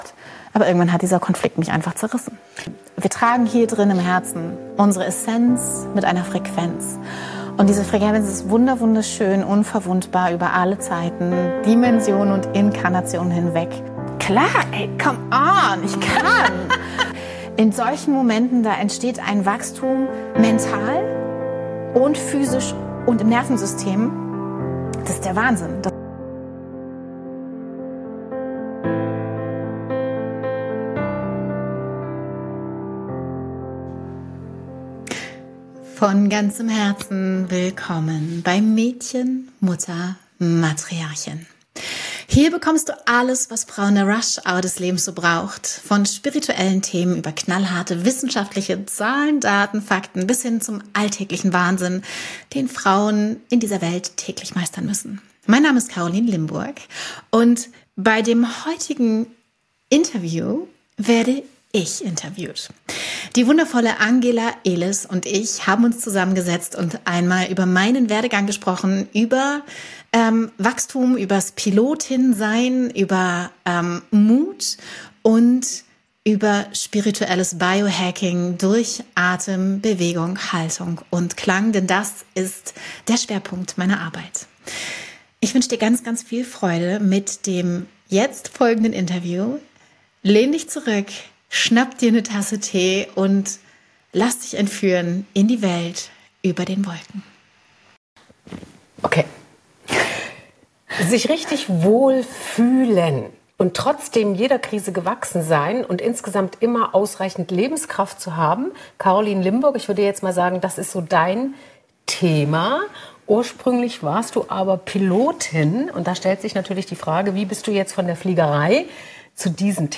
In diesem Interview teile ich meine Geschichte und gebe Einblicke in meine heutige Arbeit.